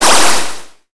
water4.wav